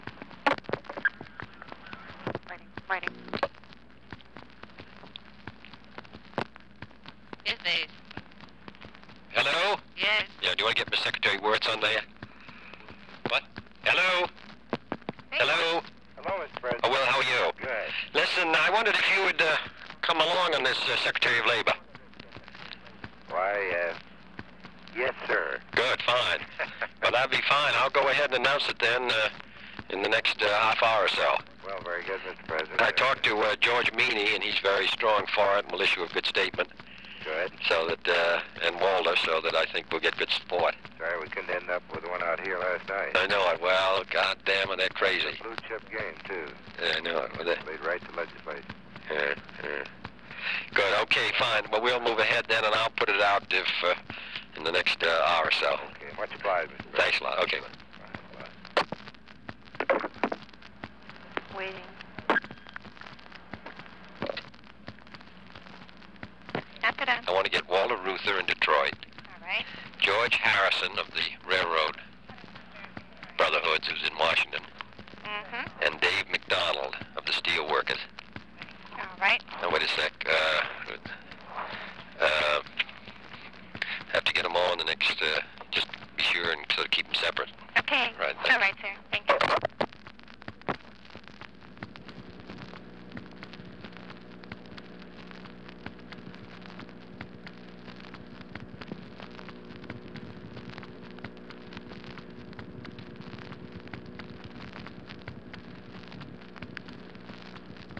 Conversation with Willard Wirtz
Secret White House Tapes | John F. Kennedy Presidency Conversation with Willard Wirtz Rewind 10 seconds Play/Pause Fast-forward 10 seconds 0:00 Download audio Previous Meetings: Tape 121/A57.